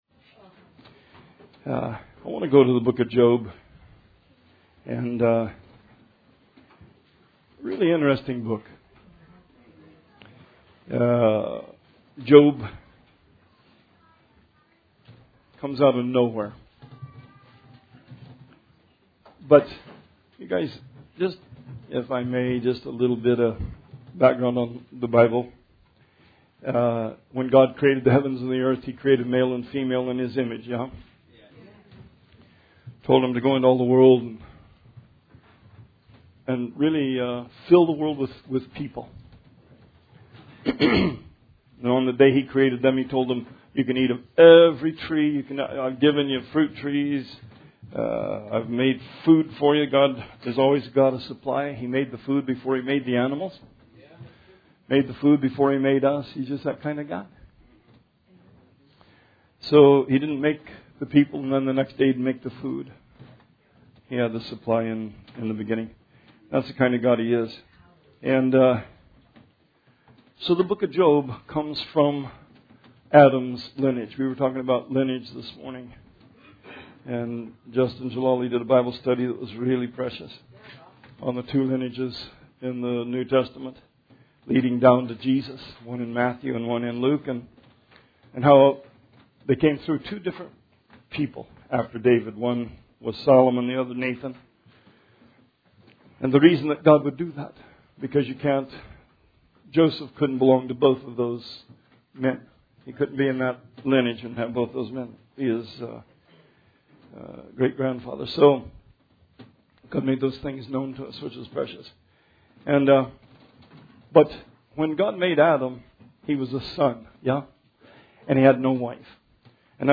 Sermon 1/26/20